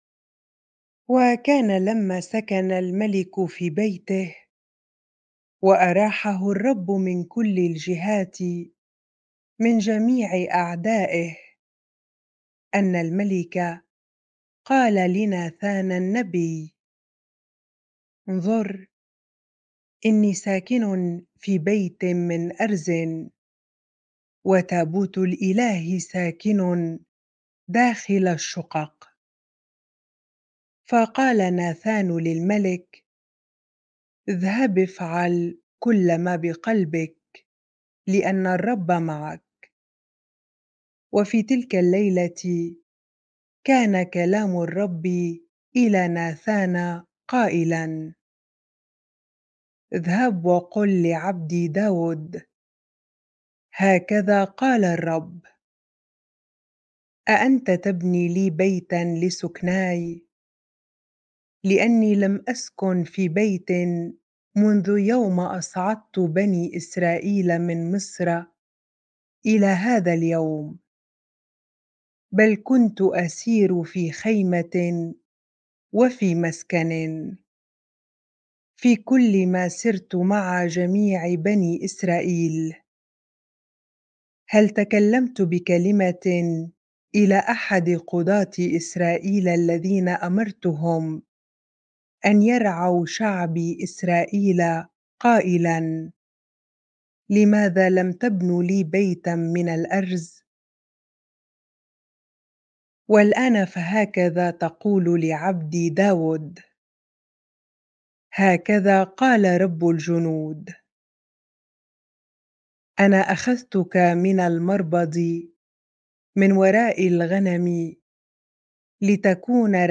bible-reading-2Samuel 7 ar